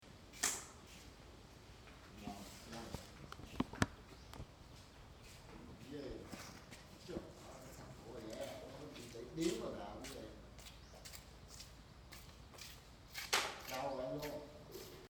03/04/2014 15:00 Près de la plage, sous un grand porche en bois, se trouve une terrasse de restaurant vide. Seuls deux hommes jouent aux cartes.